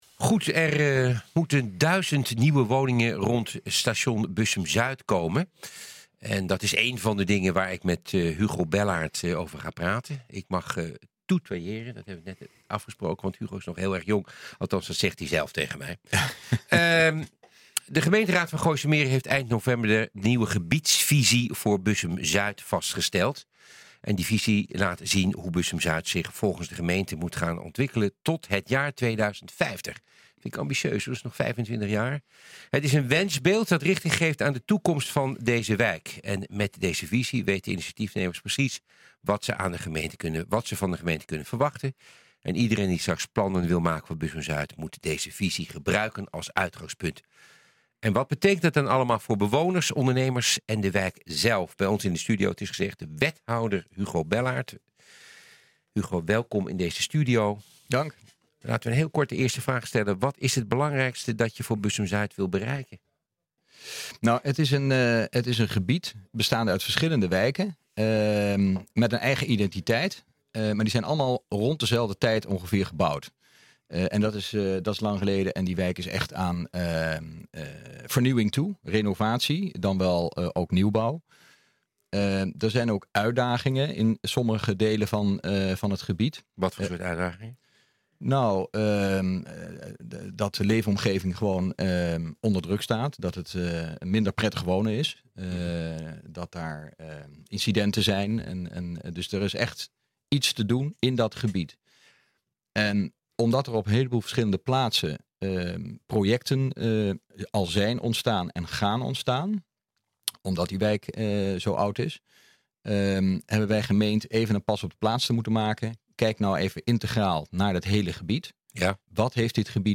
En wat betekent dat allemaal voor bewoners, ondernemers en de wijk zelf? Bij ons in de studio is wethouder Hugo Bellaart.